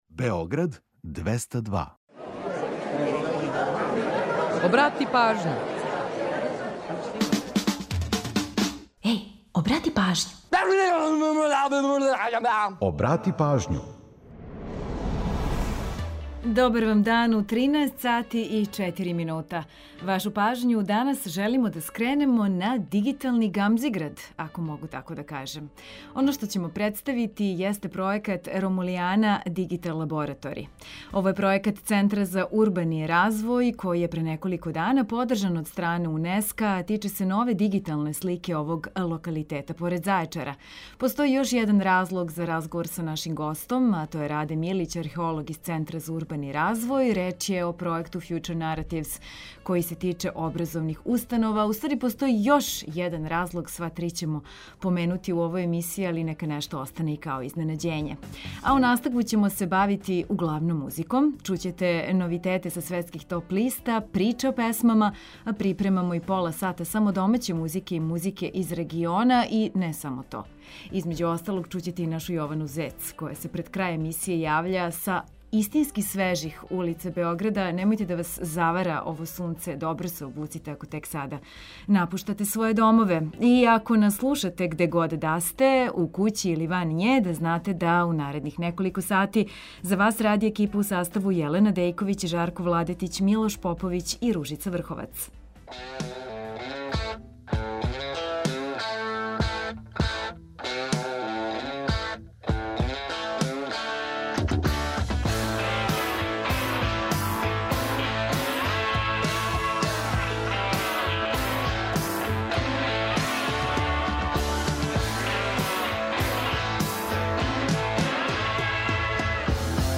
Čućete novitete sa svetskih top lista, „Priče o pesmama” a pripremamo i pola sata samo domaće muzike i muzike iz regiona.